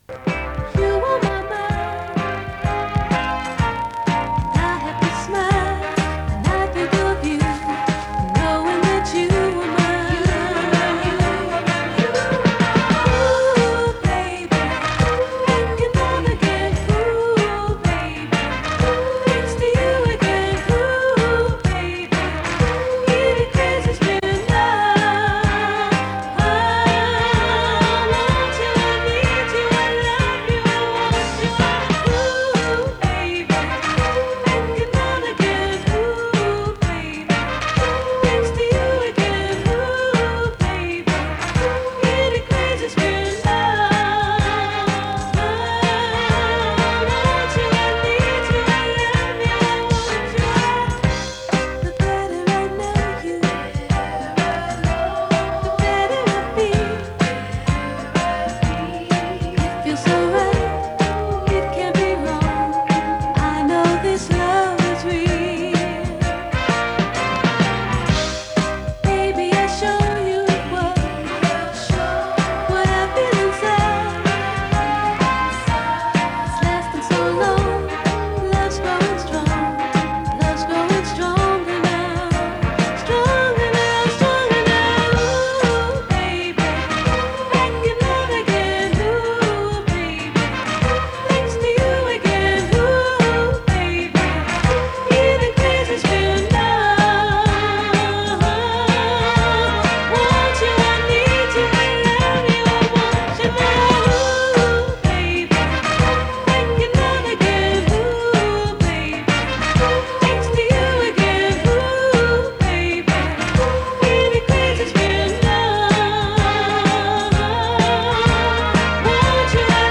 Recorded in Chingford from 90.2MHz in mono. 47MB 34mins